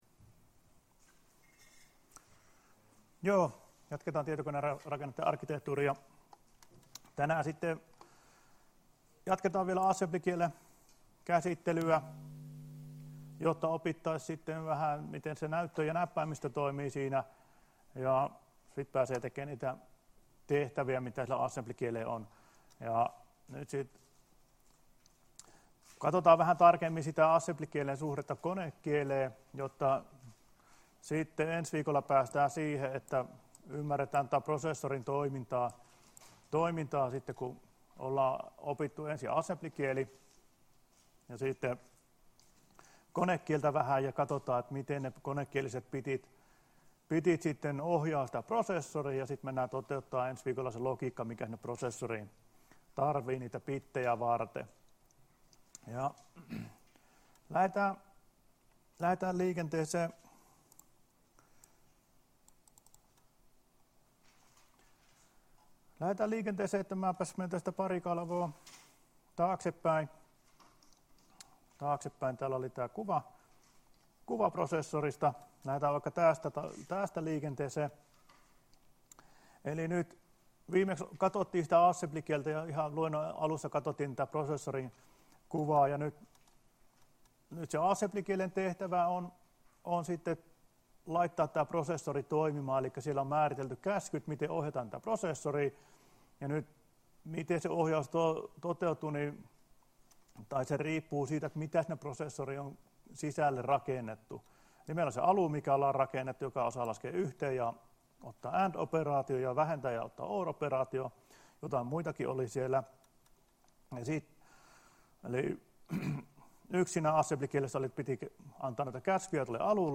Luento 29.11.2017 — Moniviestin